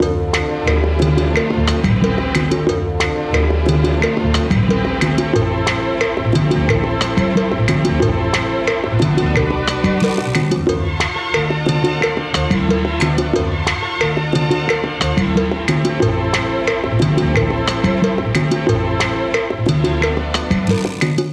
Lounge